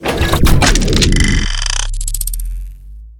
laserin.ogg